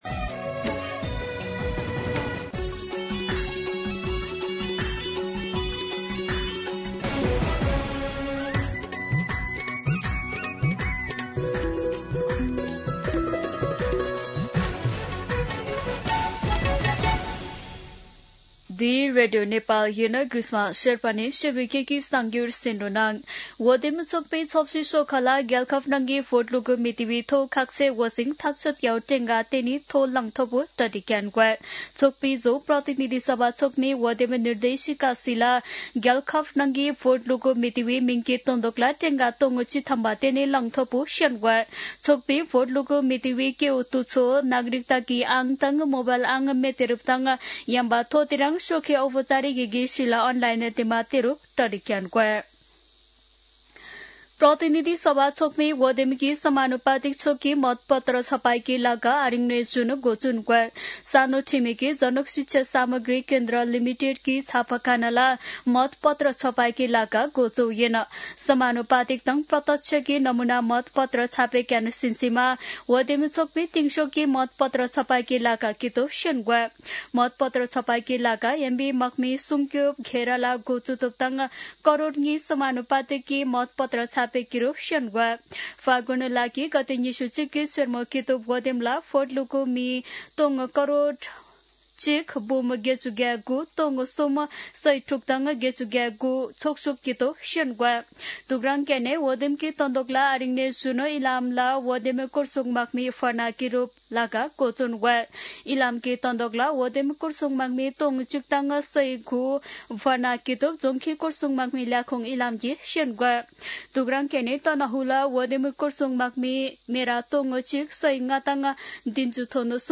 शेर्पा भाषाको समाचार : २५ पुष , २०८२
Sherpa-News-9-25.mp3